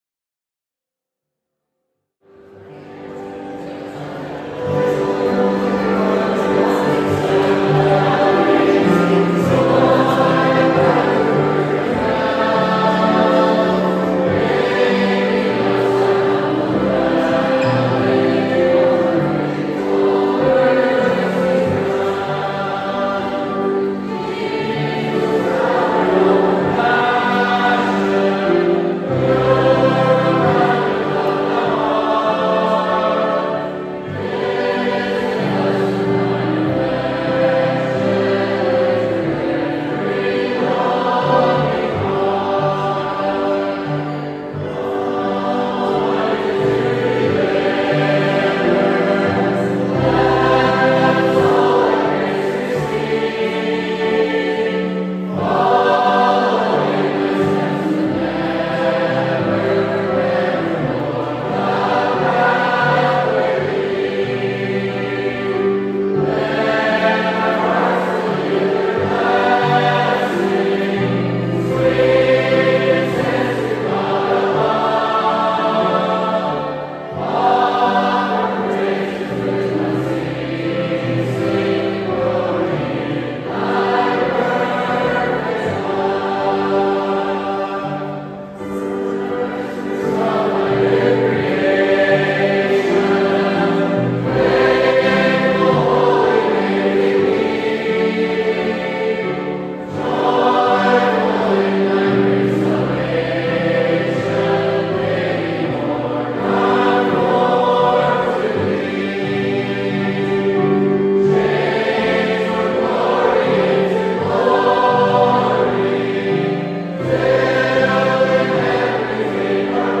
Post-conference hymn sing from the 2025 Roseisle Gospel Hall conference (MB, Canada).
Hymn singing